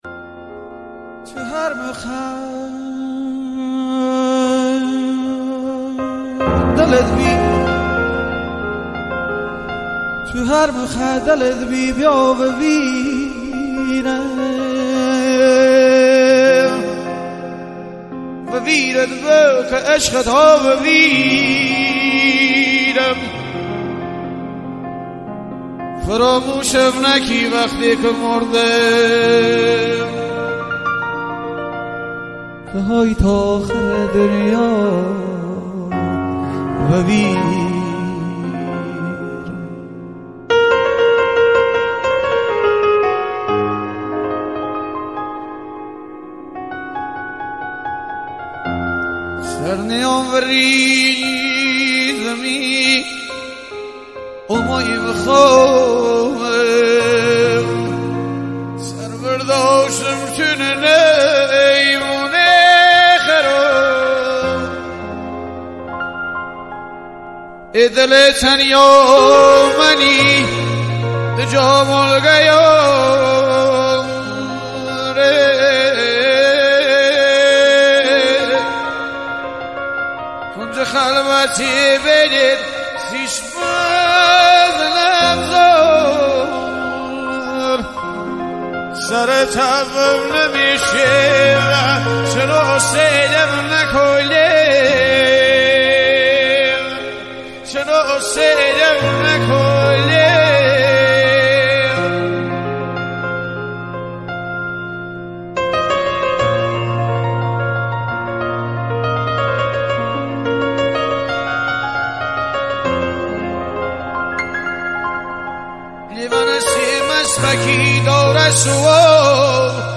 آهنگ لری غمگین